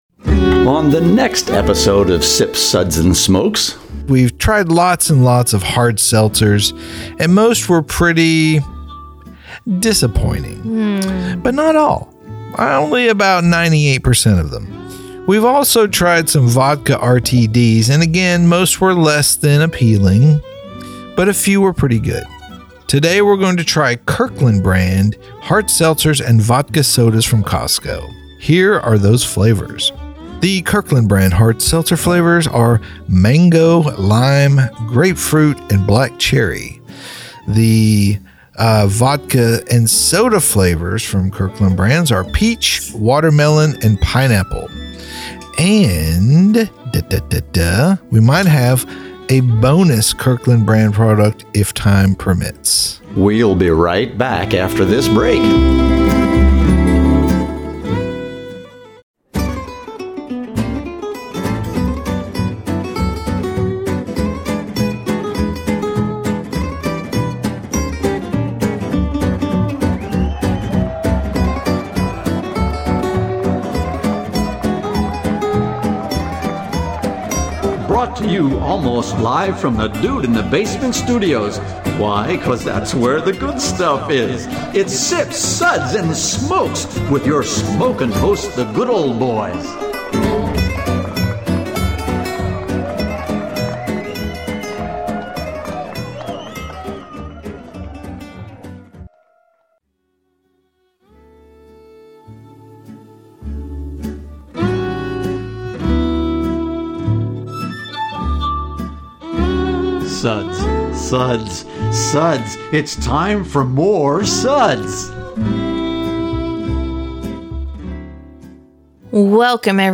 Radio MP3 with music beds